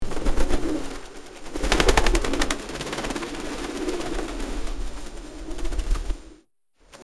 Tórtola Diamante (Geopelia cuneata)